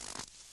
added base steps sounds
snow_4.ogg